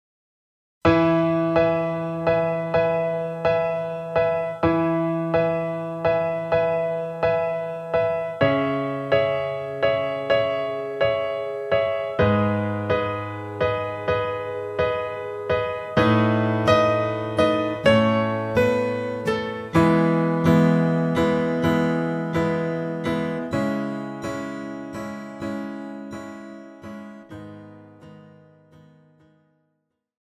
Žánr: Pop
BPM: 126
Key: E
MP3 ukázka